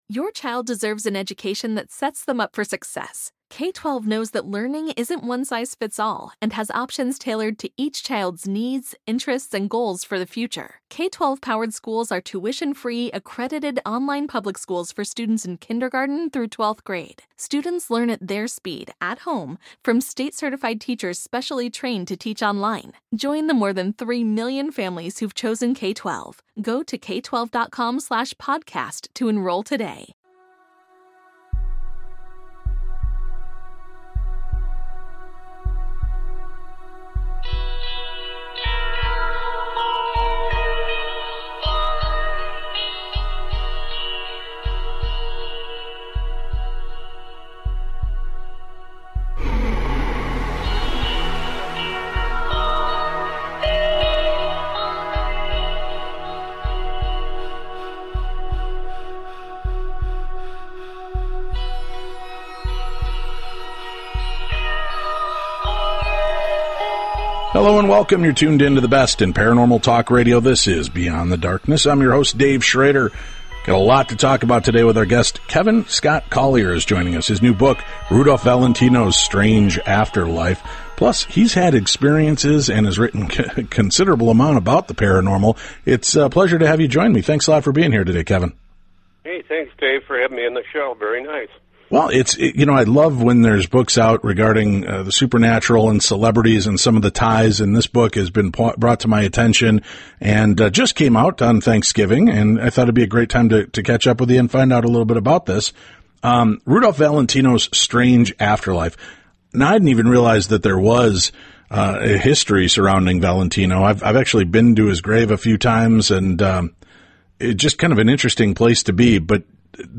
Paranormal Talk Radio